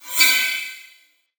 xdecor_enchanting.ogg